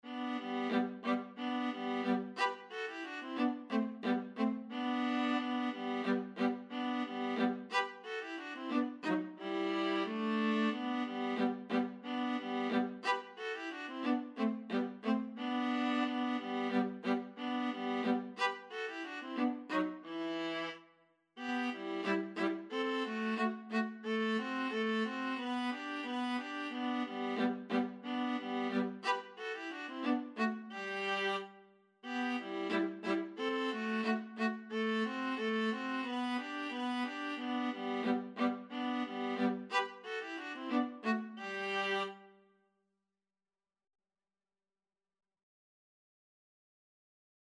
Viola 1Viola 2
Allegro risoluto = c.90 (View more music marked Allegro)
2/4 (View more 2/4 Music)
Classical (View more Classical Viola Duet Music)